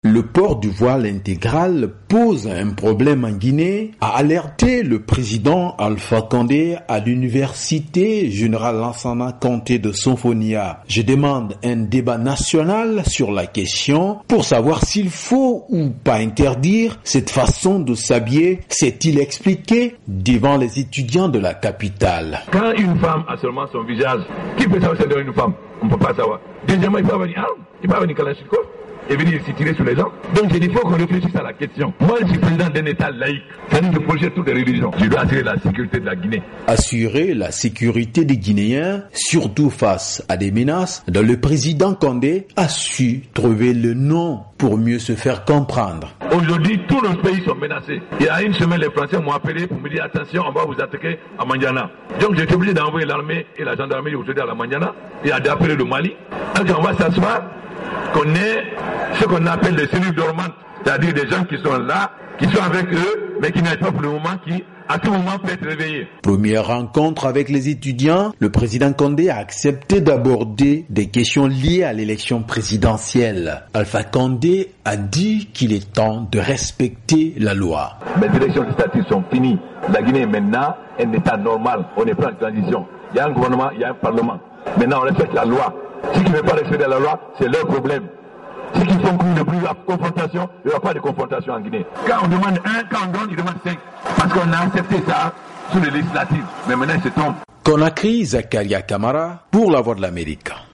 Le compte-rendu